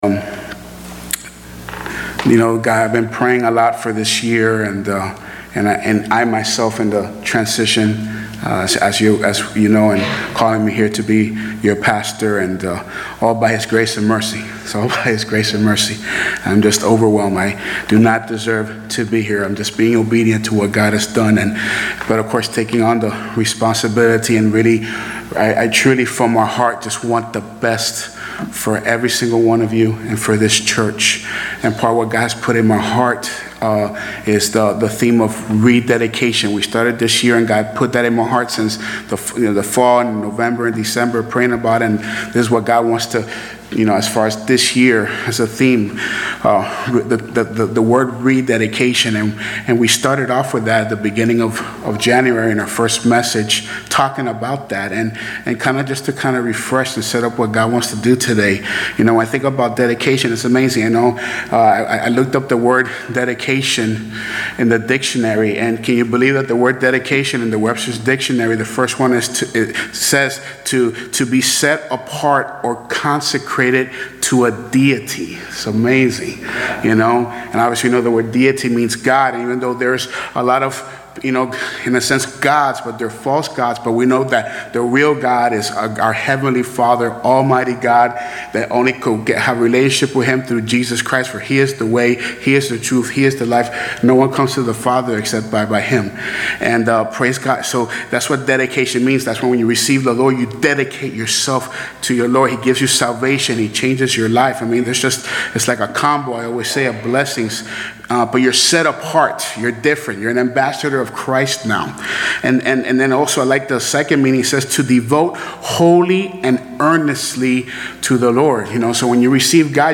Sermons - Buena Vista Baptist